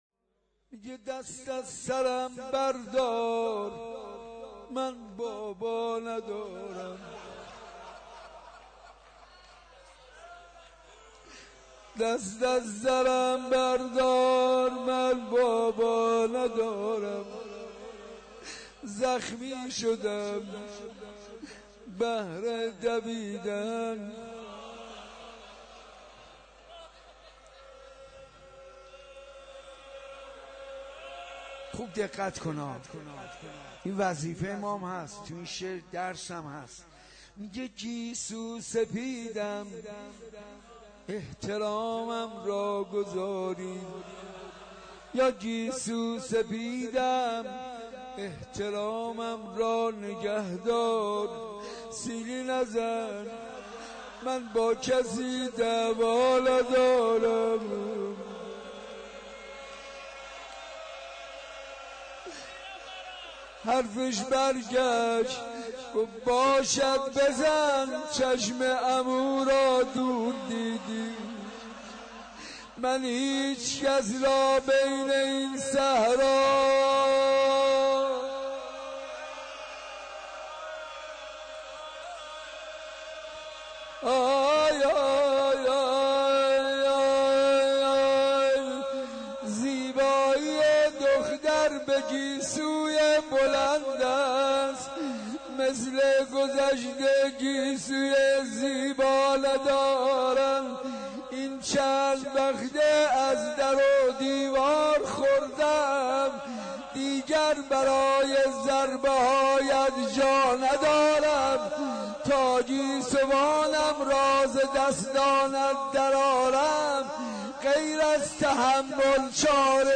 • روضه حضرت رقیه حاج منصور ارضی, صوت و کلیپ حاج منصور ارضی, نوای عرش, شب سوم رمضان 92 حاج منصور ارضی